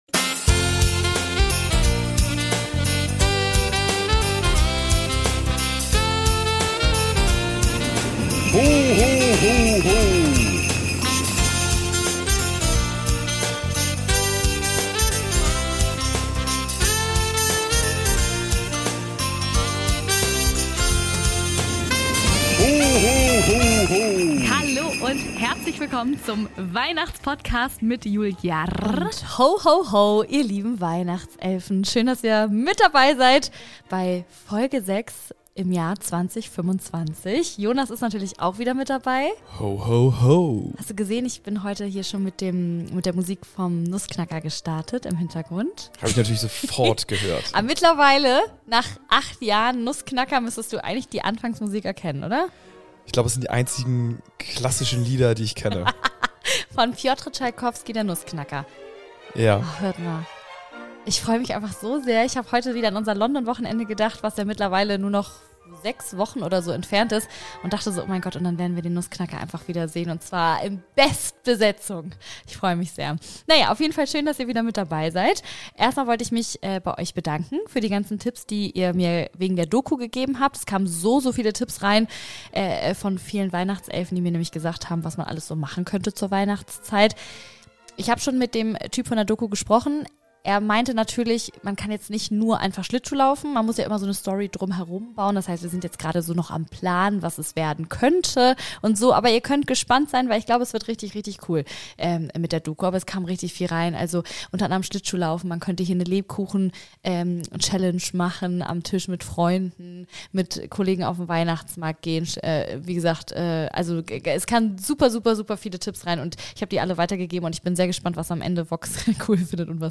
Ein paar habe ich euch hier in dieser Folge vorgespielt...